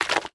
slime7.wav